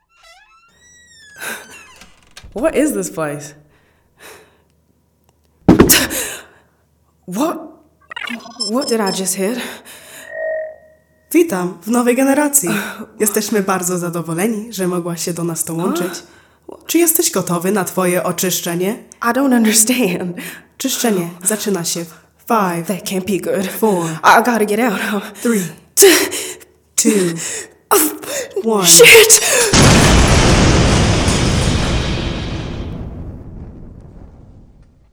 Cool and assured American voice with influence.
Computer Games